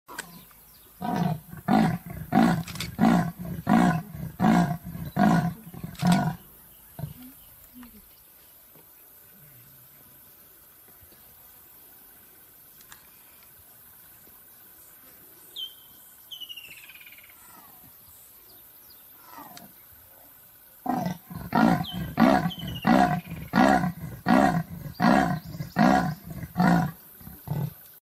3. Which animal sounds like sawing wood?
These elusive and solitary cats are usually silent–but their iconic hacksaw roar is hoarse and guttural, intended to show dominance when threatened or mark their territory.
leopard-sound2.mp3